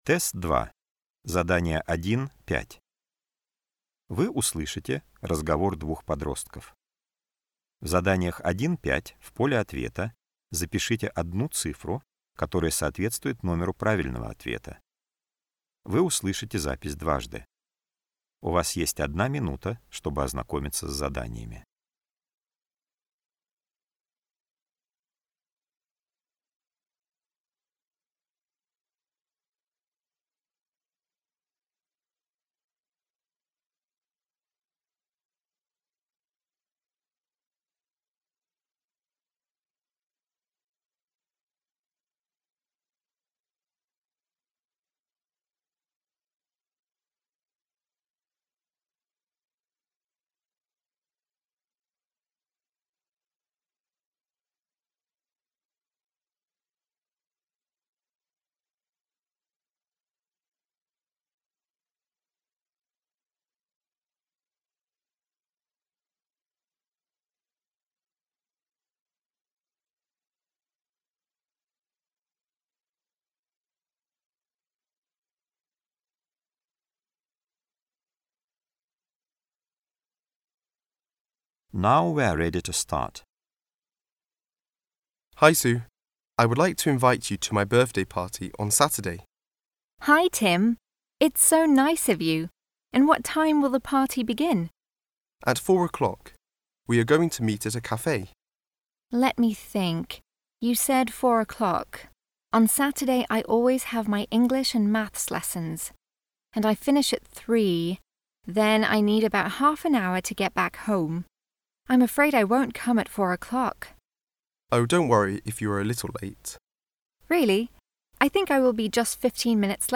Вы услышите разговор двух подростков.